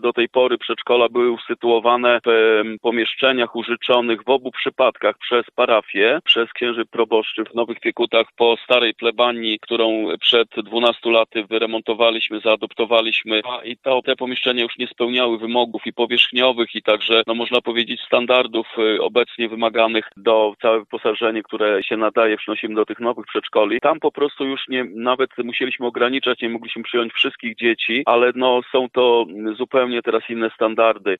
Wójt Gminy, Marek Kaczyński mówi, że to była bardzo potrzebna inwestycja.